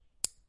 OW音效库180117 " 剪钉子
描述：用指甲刀剪断左手钉。 用Zoom H6录制
Tag: SNAP 手指 脚趾 剪断 手指 指甲 紧缩 夹子 OWI